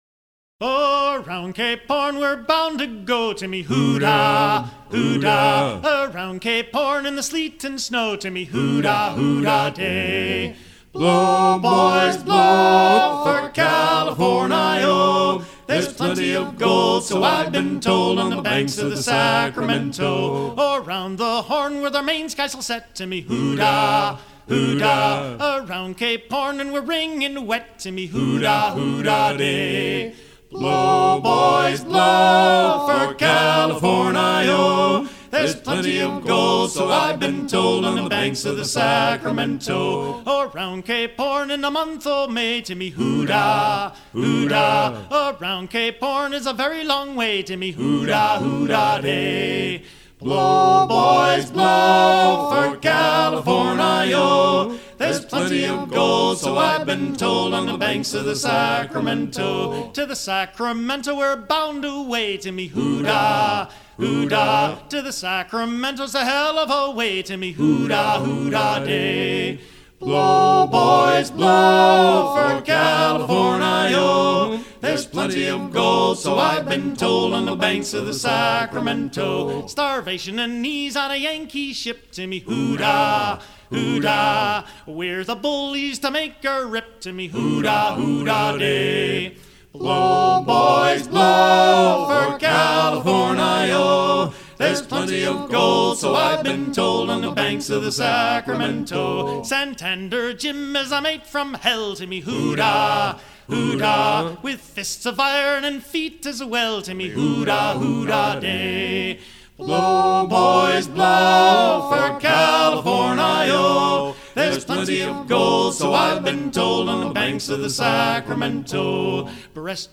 à virer au cabestan
Pièce musicale éditée